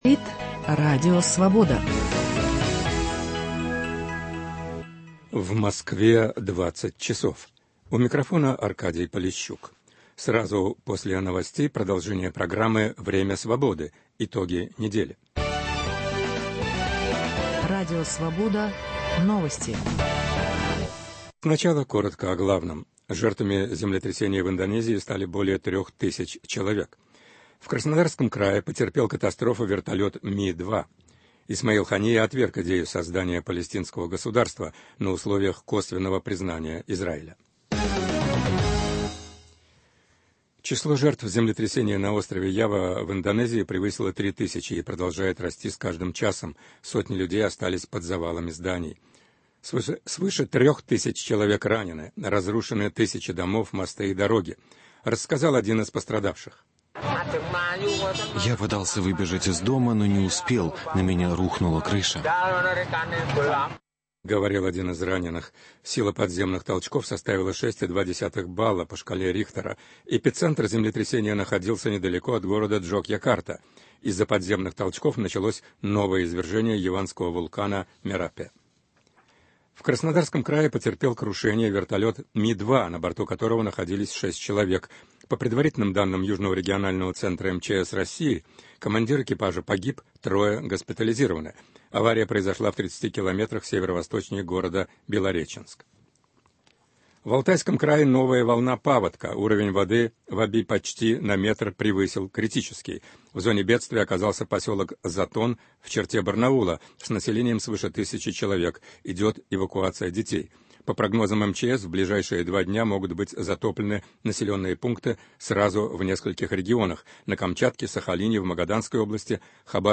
Дискуссия с экспертами о саммите ГУАМ и кризисе СНГ, новости шоу-бизнеса, обзоры российских Интернет-изданий и американских еженедельников